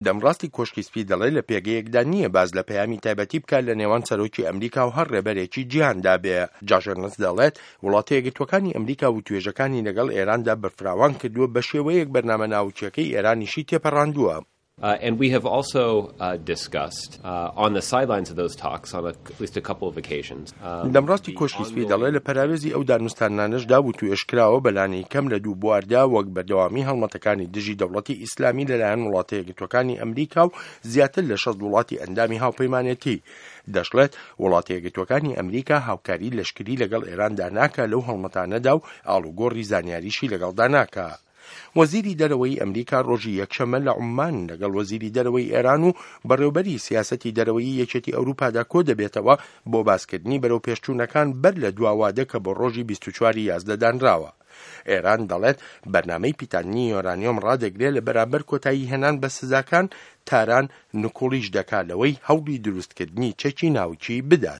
ڕاپـۆرتی نامه‌ی سه‌رۆک ئۆباما